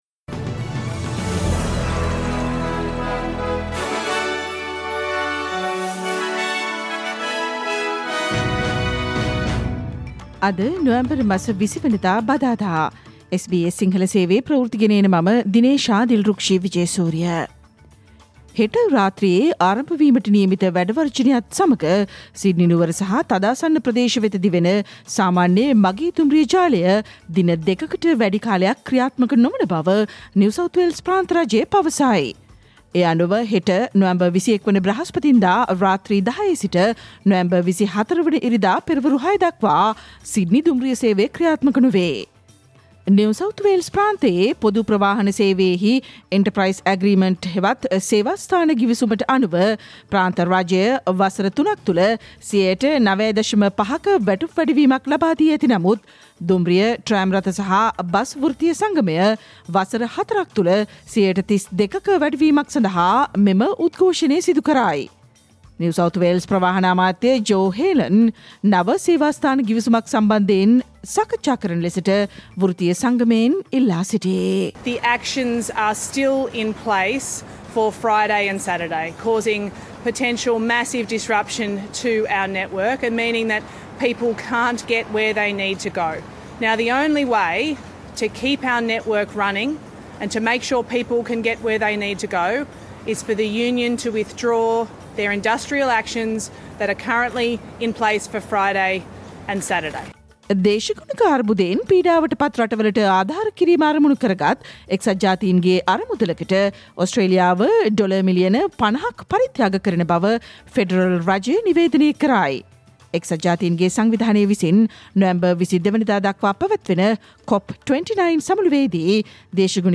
Australian news in Sinhala, foreign and sports news in brief - listen, SBS Sinhala radio news on Wednesday 20 November 2024